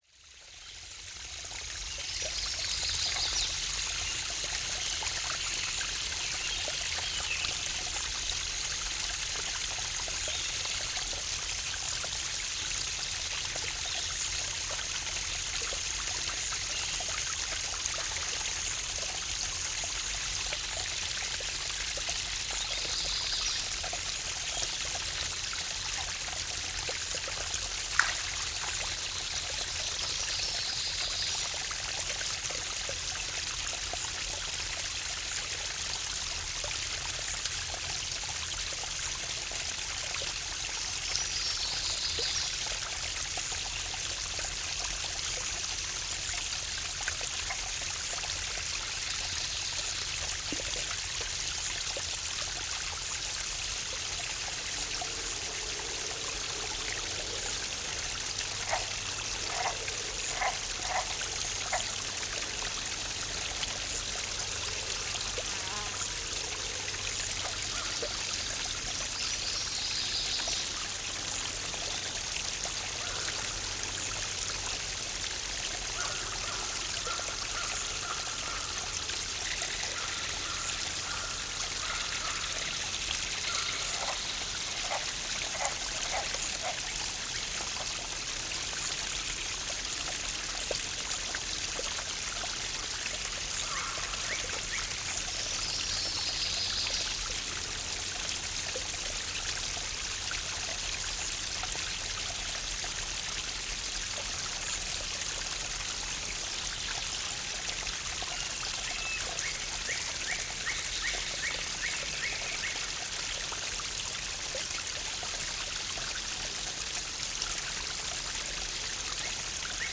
Thought I would try a contrived recording with this one.